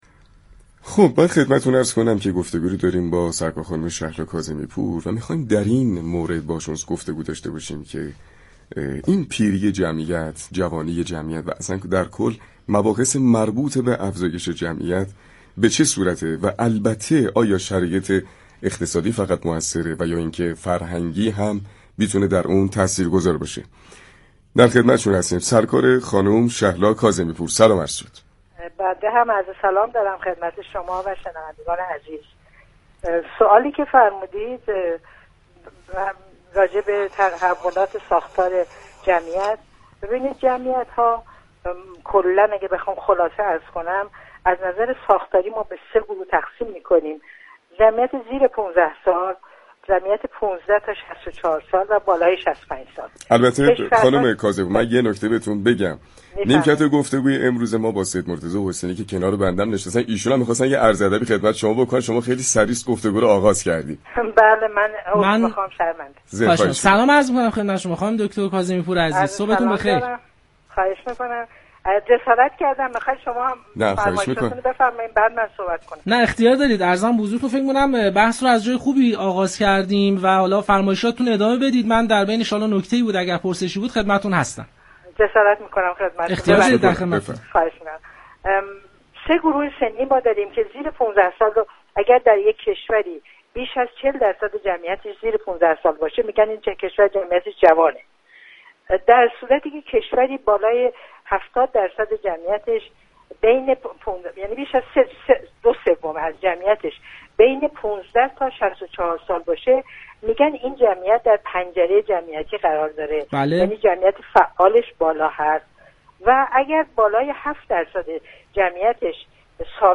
در گفتگو با پارك شهر رادیو تهران